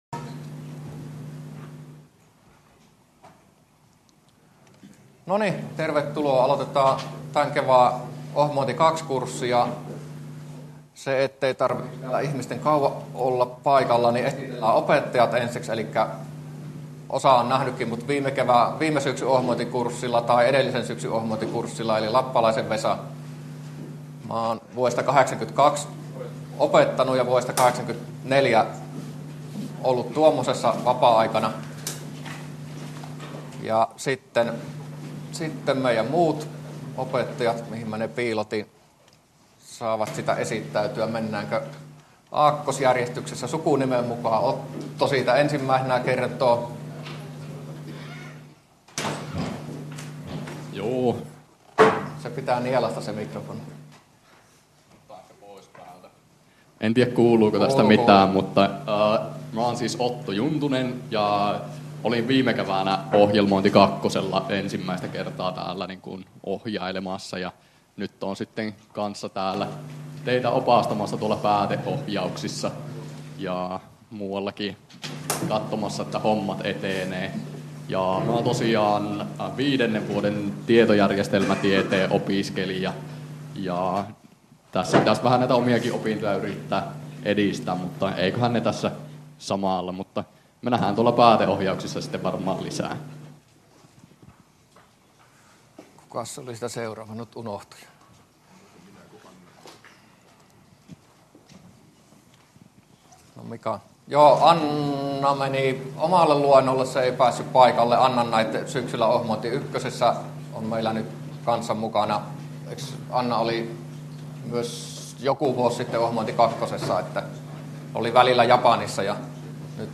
luento01